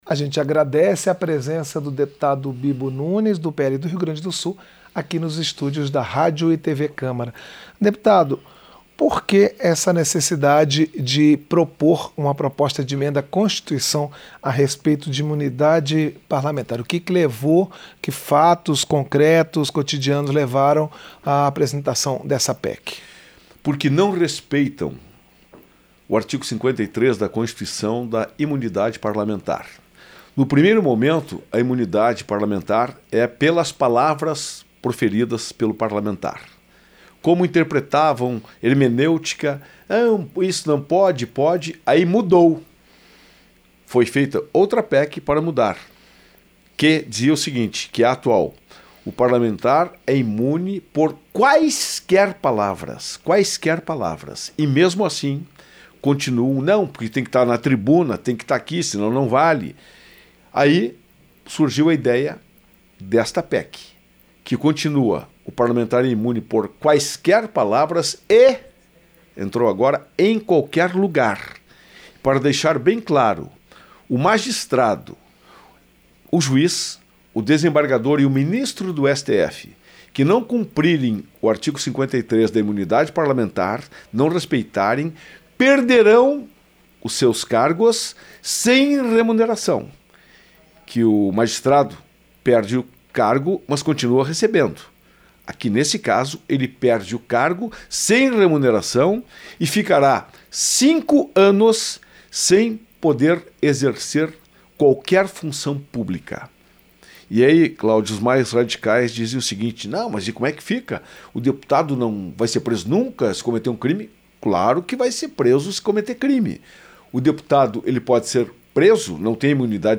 Entrevista - Dep. Bibo Nunes (PL-RS)